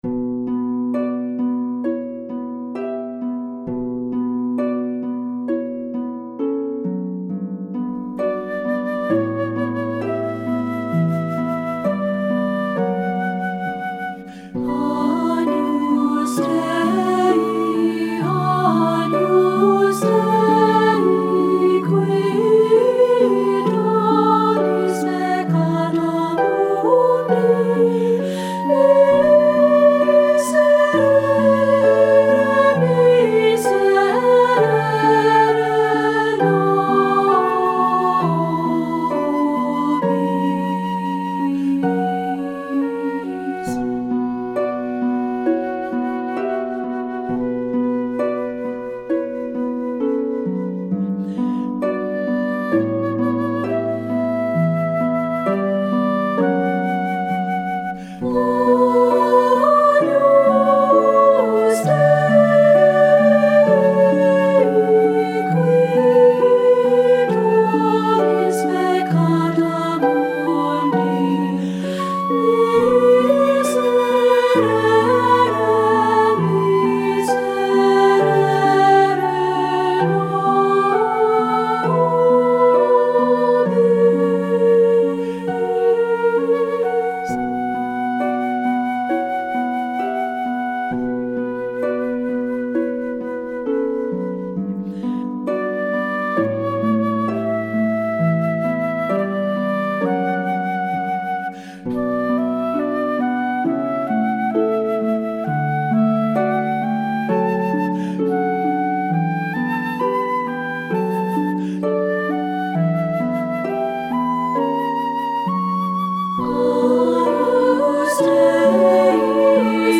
SA Voices with Piano or Harp and Optional Flute
• Soprano
• Alto
• Piano
Studio Recording
Ensemble: Treble Chorus
Accompanied: Accompanied Chorus